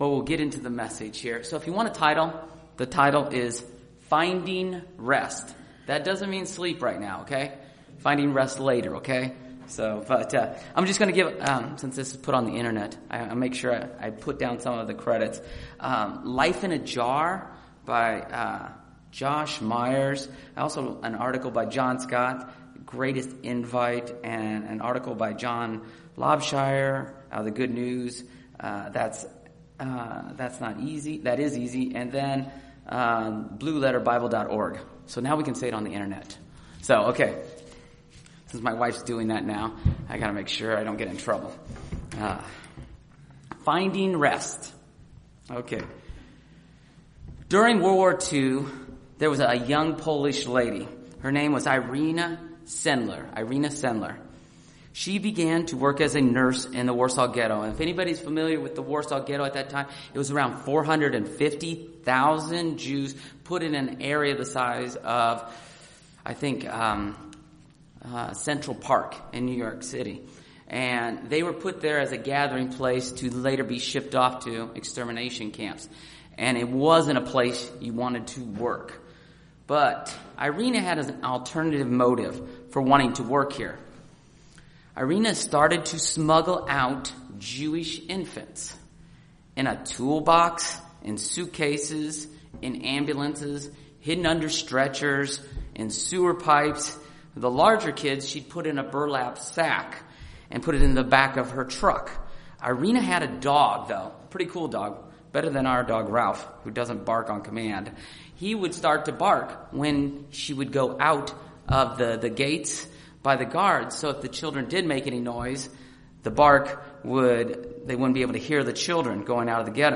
How do we find true rest in a stress filled world? This sermon provides three points to help you achieve this.